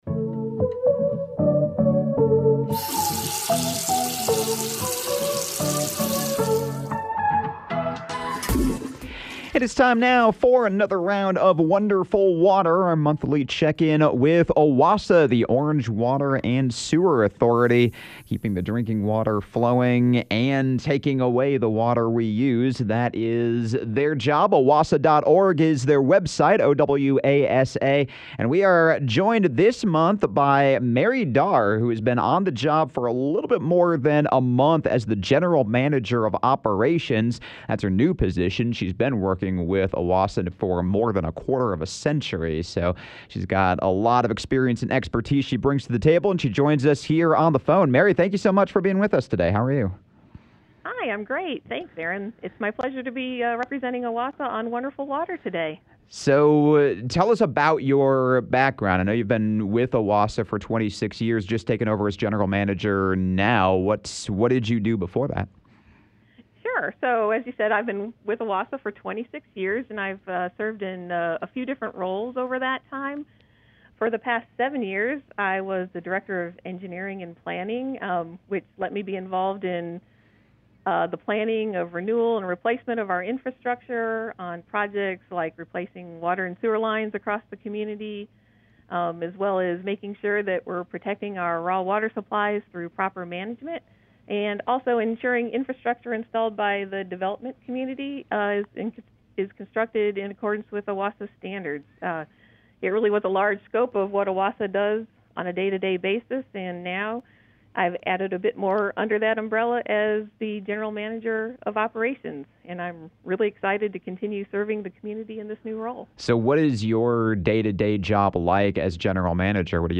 Chapel Hill and Carrboro residents use roughly 7 million gallons of water a day, and “Wonderful Water” is a monthly conversation sponsored by the Orange Water and Sewer Authority highlighting its work to keep our community growing and water flowing.